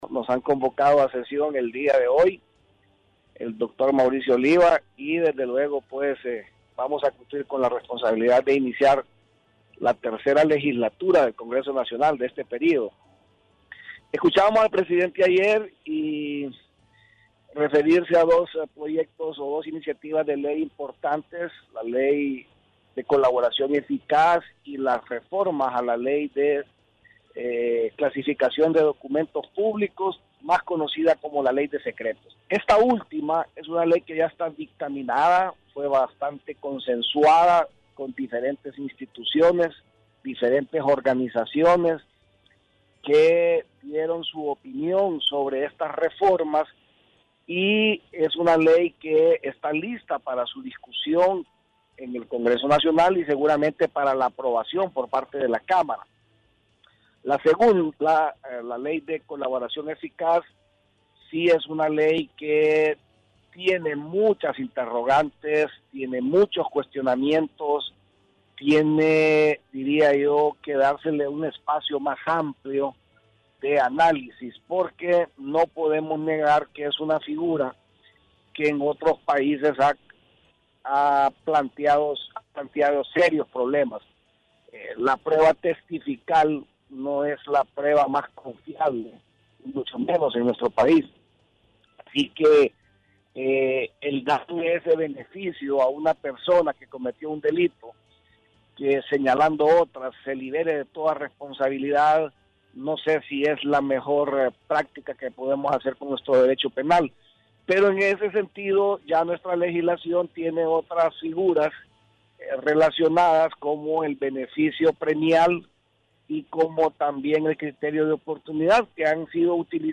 Declaraciones del diputado, Mario Pérez.